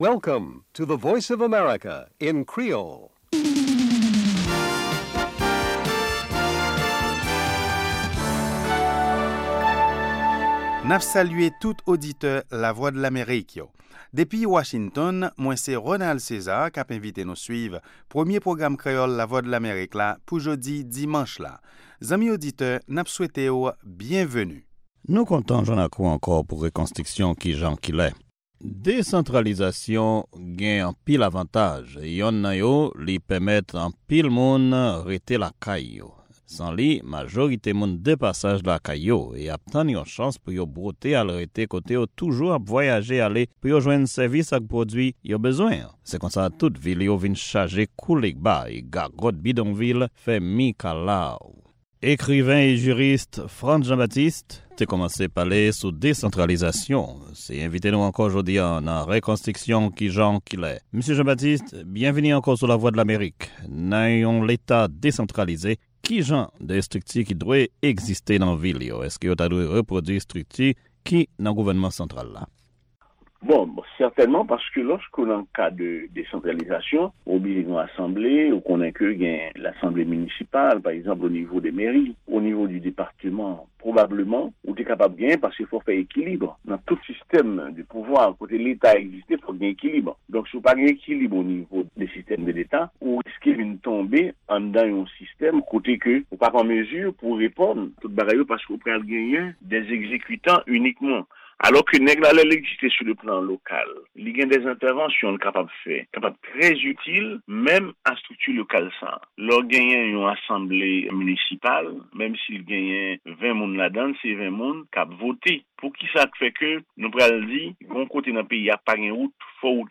Se 2èm pwogram jounen an, avèk nouvèl tou nèf sou Lèzetazini, Ayiti ak rès mond la. 2 fwa pa semèn (mèkredi ak vandredi) se yon pwogram lib tribin "Dyaloge ak Etazini", sou Ayiti oubyen yon tèm enpòtan konsènan Lèzetazini ou rejyon Amerik Latin nan.